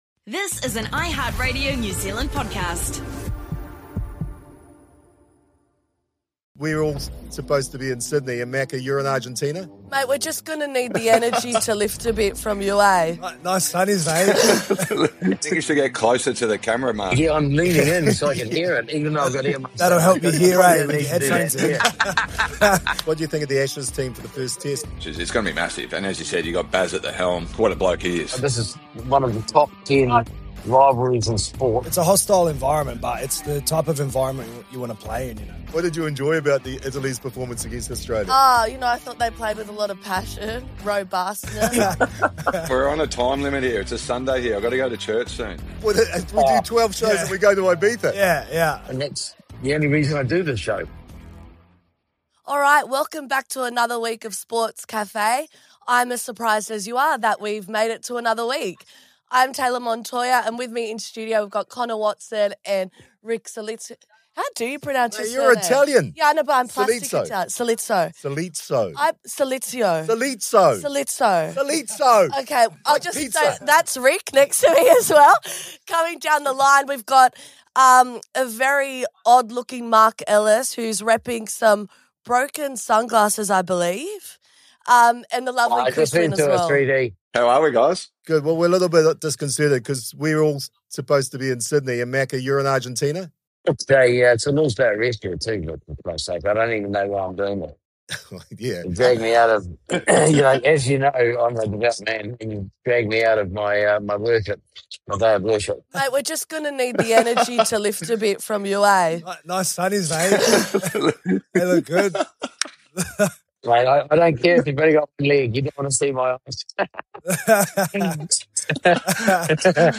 R360 and NRL signings is the new soap opera starring Payne Haas, Dale Cherry-Evans and Reece Walsh. Meanwhile… Marc Ellis checks in LIVE from a bar in Argentina wearing AirPods and attends a legendary Boca Juniors game to give his emotional report.